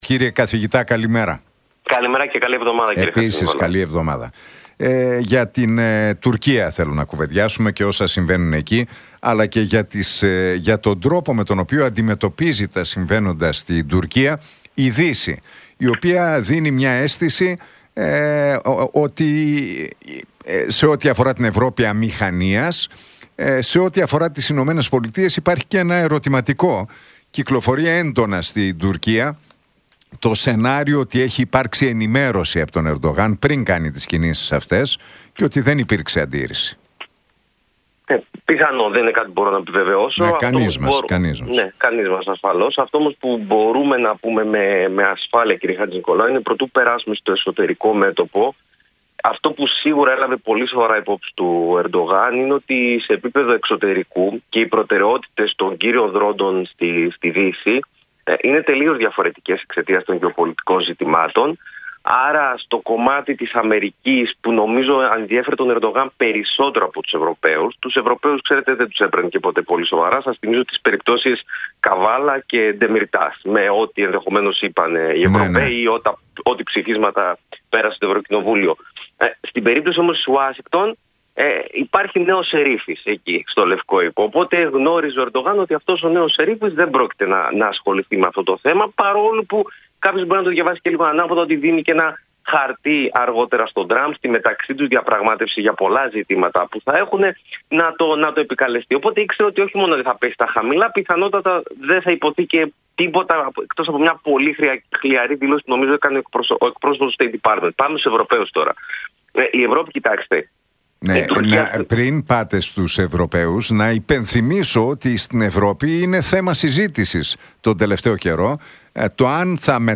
στον Νίκο Χατζηνικολάου από την συχνότητα του Realfm 97,8.